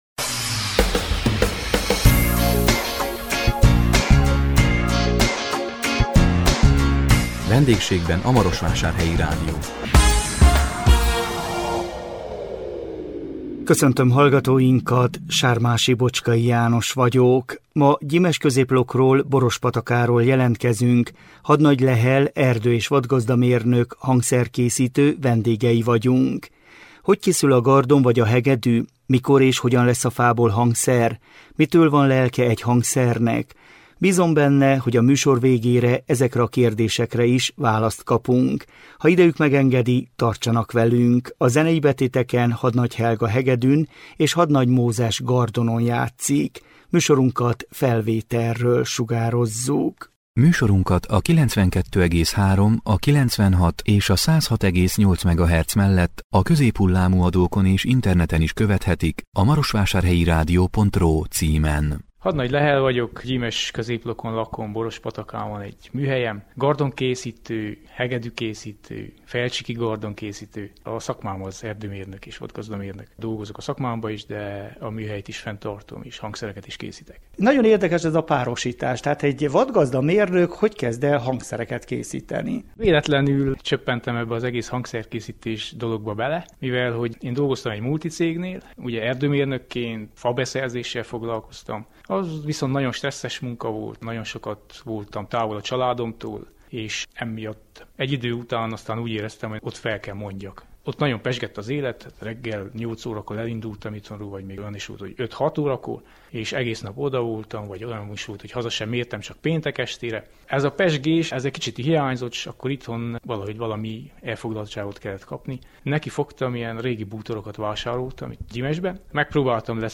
A 2025 július 3-án közvetített VENDÉGSÉGBEN A MAROSVÁSÁRHELYI RÁDIÓ című műsorunkkal Gyimesközéplokról, Borospatakáról jelentkeztünk